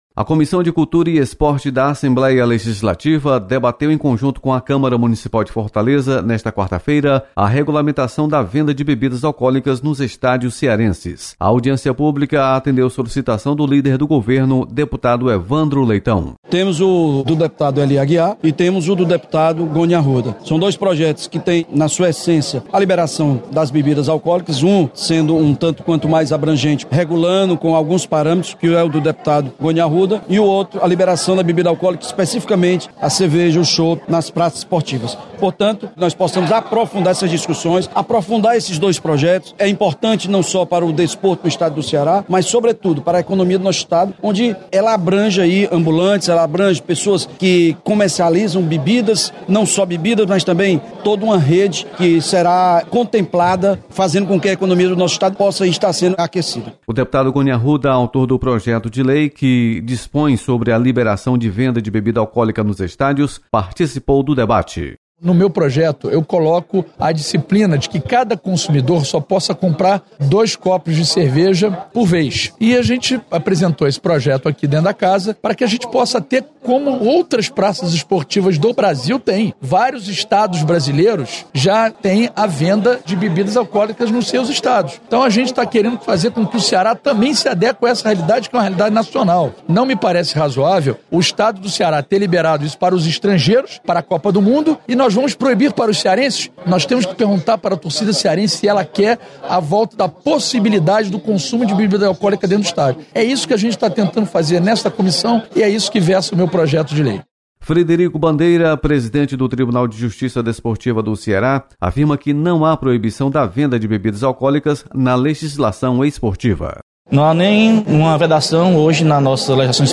Comissão de Cultura e Esporte debate regularização da venda de bebidas alcoólicas nos estádios do Ceará. Repórter